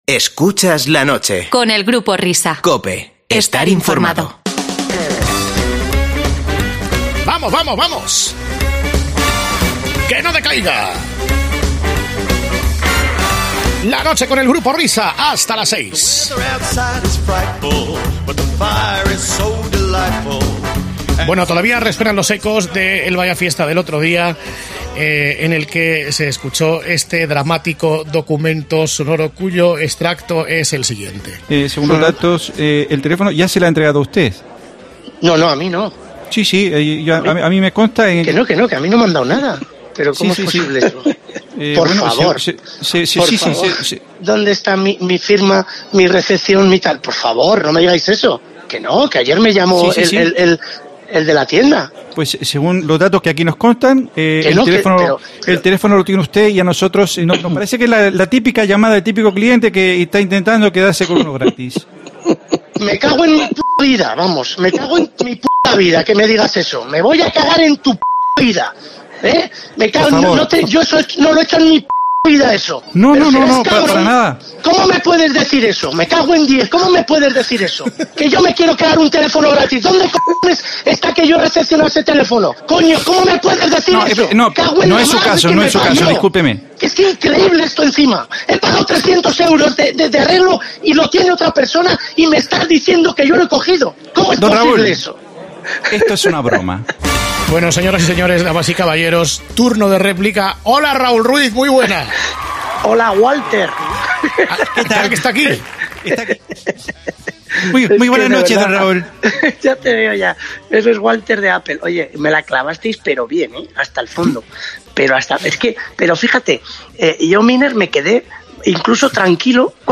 No os perdáis esta charla.